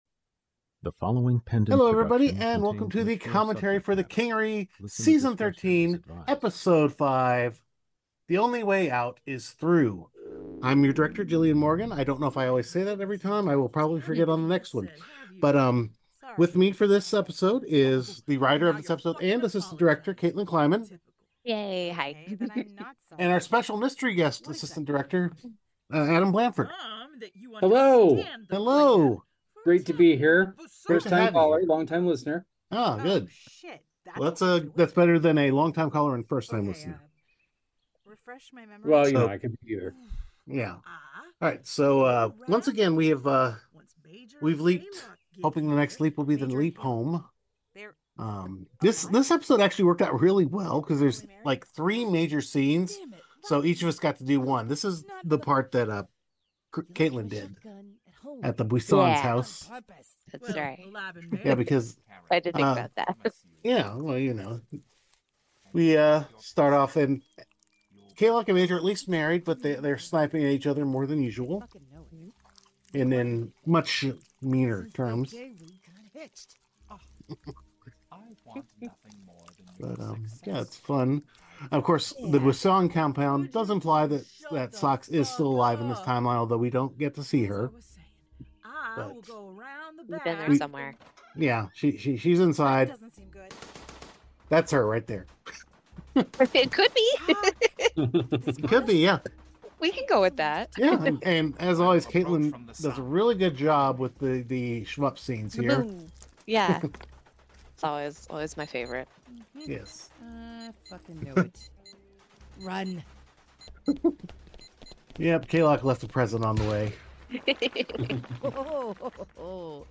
The Kingery 13x05 COMMENTARY